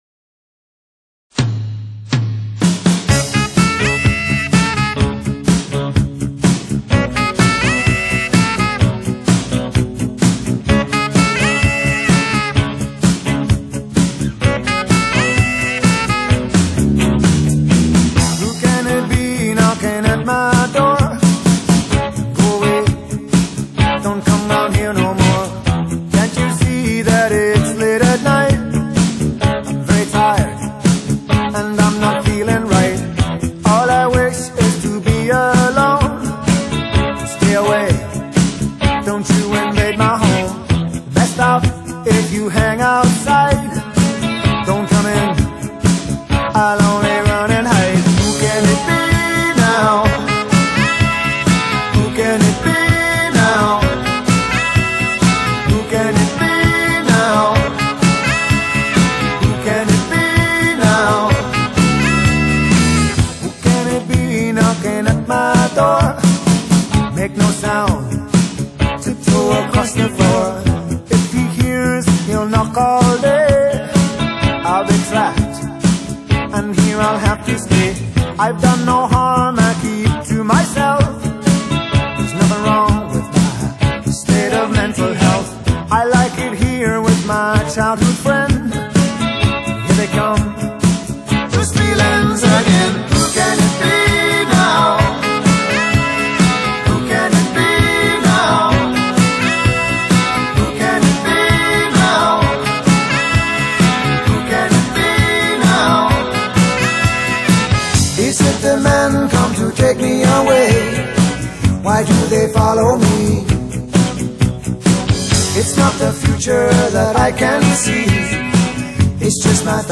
Rock, Pop Rock, New Wave